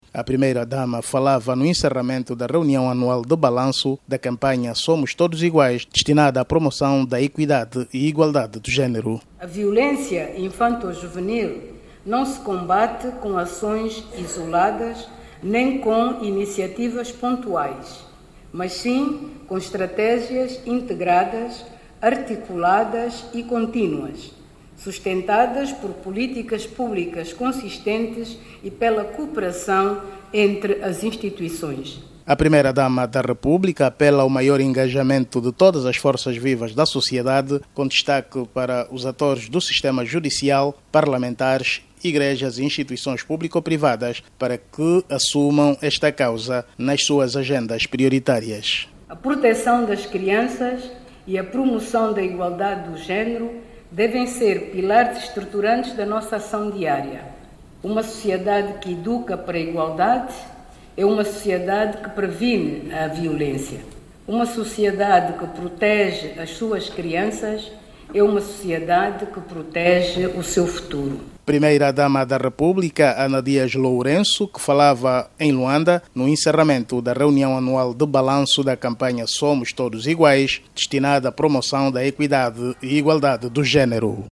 A Primeira-Dama da República, Ana Dias Lourenço, defende a implementação de políticas públicas consolidadas para o combate de todas as formas de violência contra a criança. Para a Primeira-Dama, acções isoladas não contribuem para a protecção da criança. Saiba mais dados no áudio abaixo com o repórter